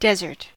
En-us-desert-noun.ogg.mp3